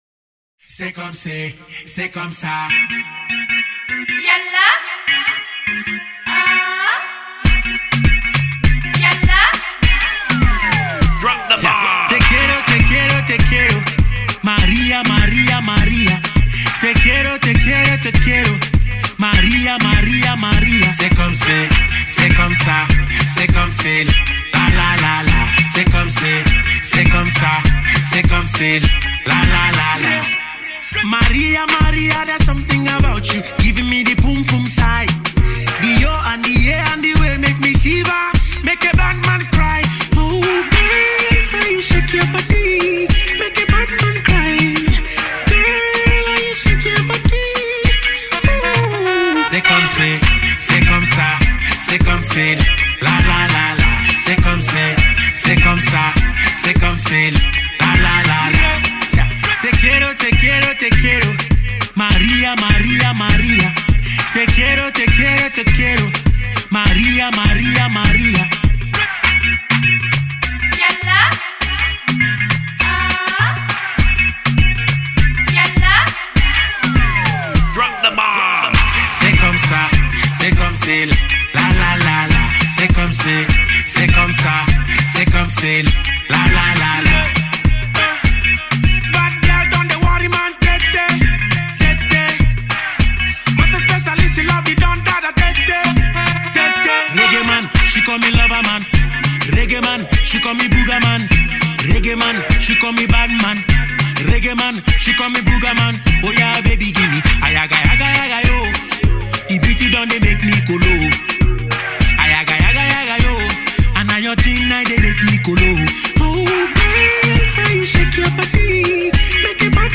a Spanish inspired love song